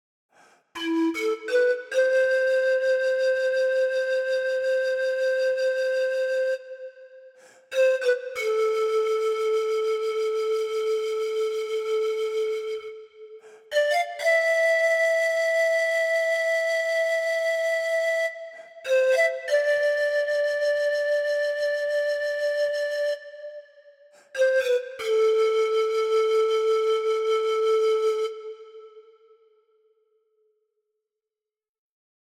Chromatic Pan Flute features a large Peruvian pan flute, ready to play right away.
The medium dynamic layer is triggered by standard playing with medium velocity on your keyboard, with the samples featuring a natural, moderate vibrato.
Listen to – Medium Dynamic Layer